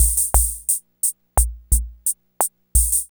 TR55BOSSAB0R.wav